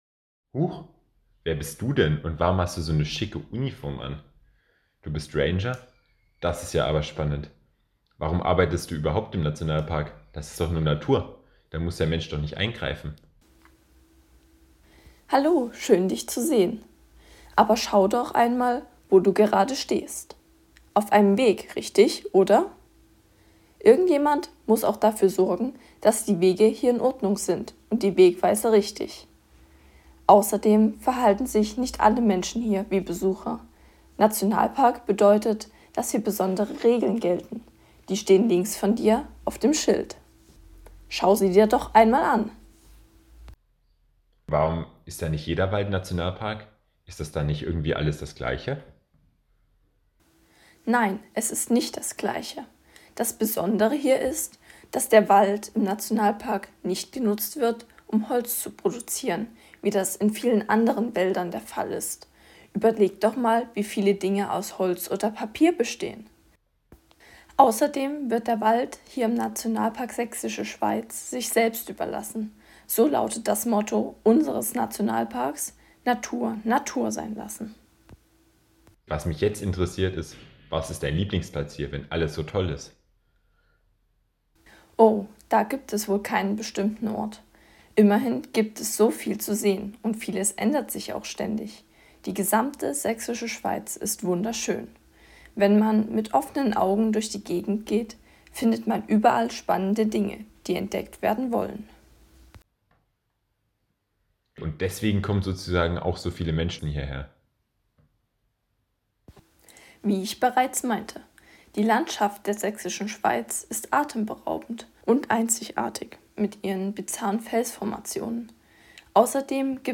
Billy trifft einen Nationalparkranger.
Ranger-bearbeitet-mit-Gegenpart.m4a